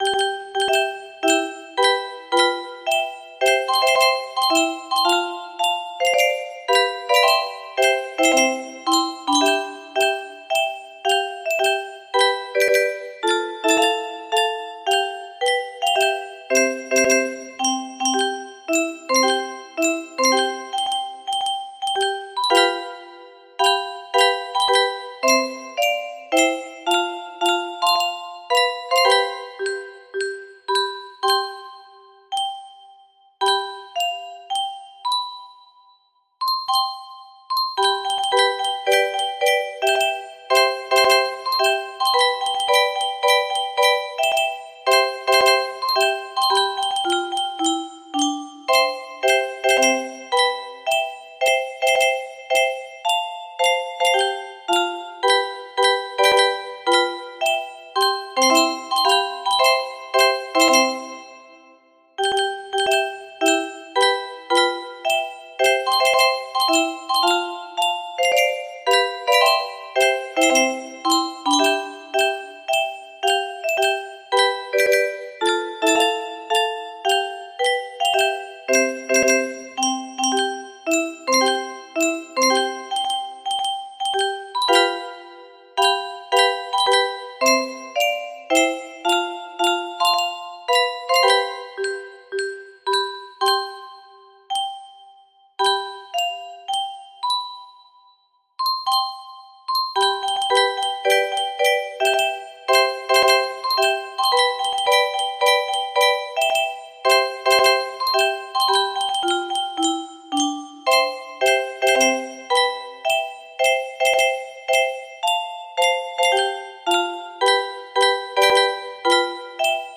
Marseillaise militaire music box melody